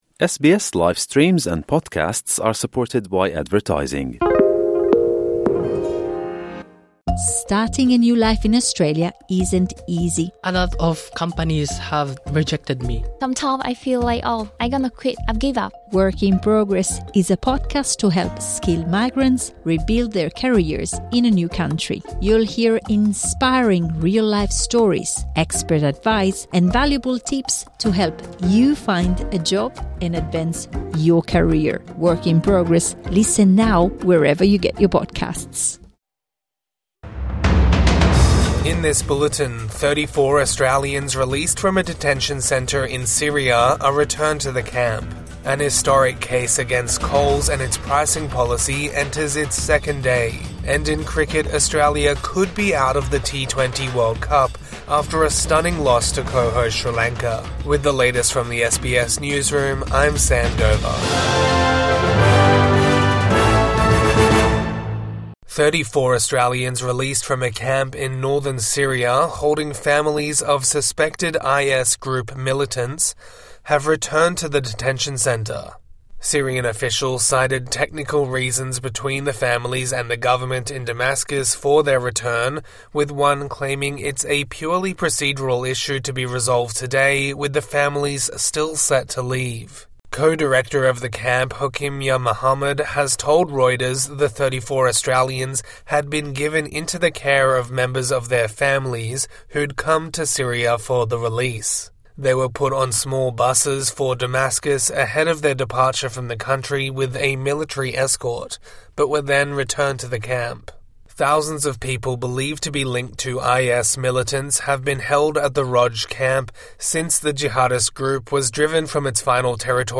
Families of suspected IS fighters returned to detention camp | Morning News Bulletin 17 February 2026